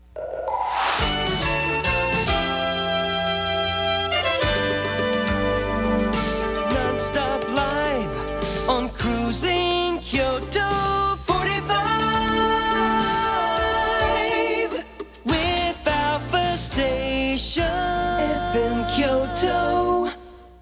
音源は全てステレオ録音です。
全体を通しての感想ですが、ジャズを基調としたとても贅沢な楽曲となっています。